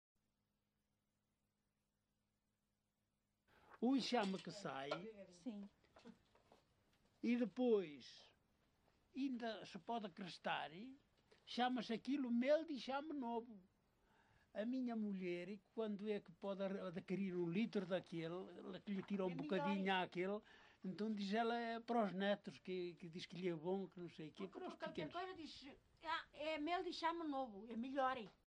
LocalidadeCastro Laboreiro (Melgaço, Viana do Castelo)